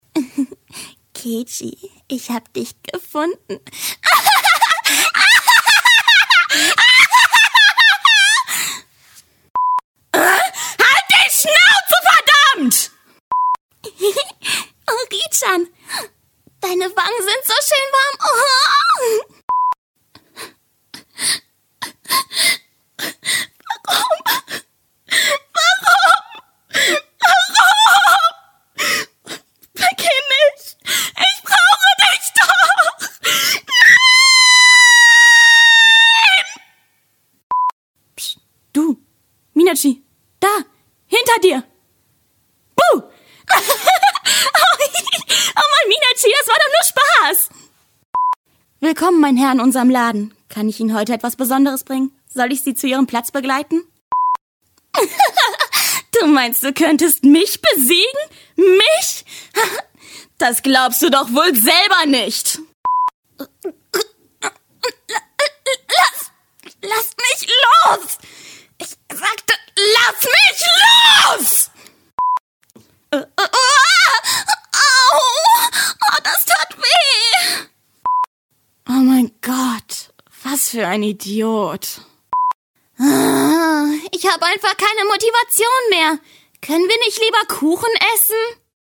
UK Olympus Imageclip